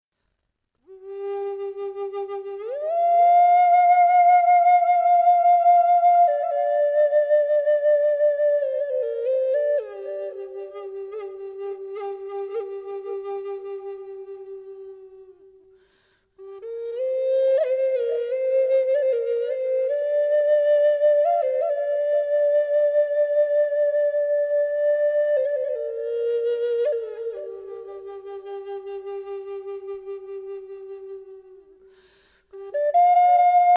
Ideal for relaxation and/or meditation